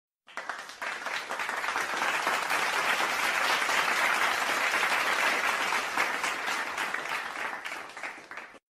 clap.mp3